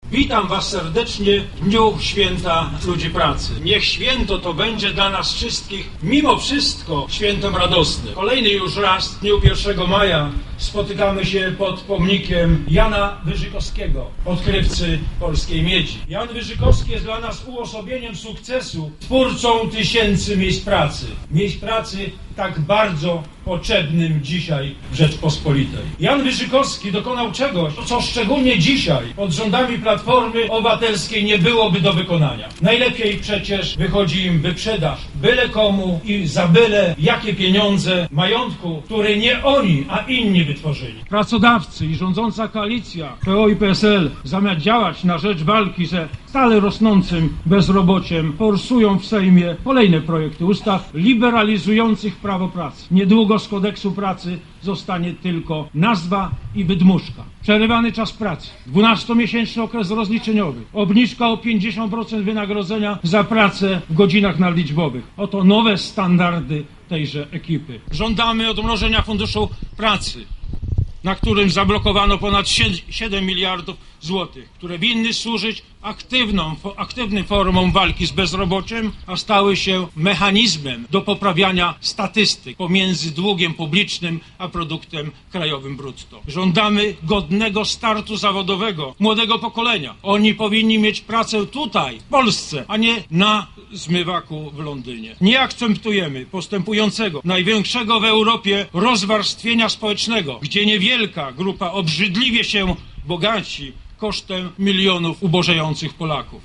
Obchody 1 Maja tradycyjnie zorganizowano pod pomnikiem Jana Wyżykowskiego.
Podczas swojego wystąpienia lubiński parlamentarzysta wytknął koalicji PO-PSL szereg błędów i zaniechań, które - jego zdaniem - doprowadziły do zapaści gospodarczej kraju.